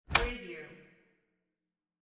Closet Door Open Wav Sound Effect #5
Description: The sound of a closet door pulled open
Properties: 48.000 kHz 16-bit Stereo
Keywords: closet, door, open, opening, roller, catch
closet-door-open-preview-5.mp3